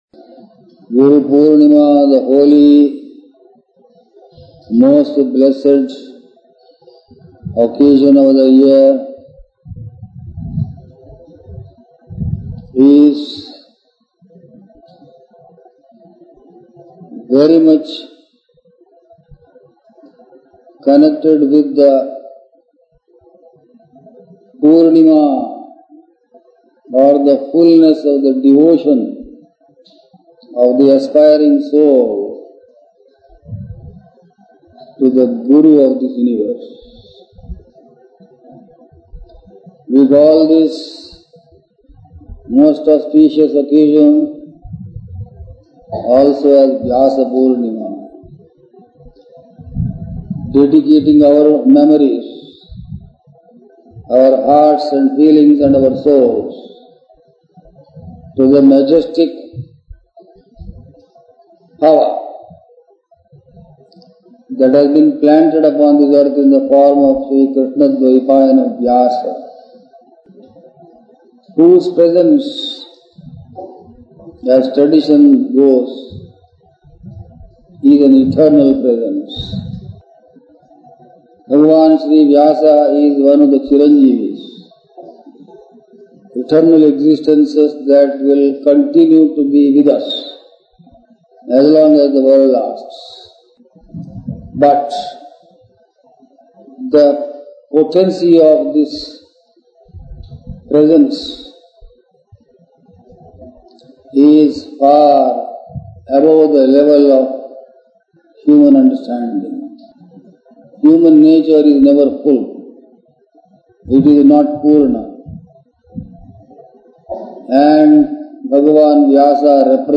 Discourses (Continued) Audios of Swami Krishnananda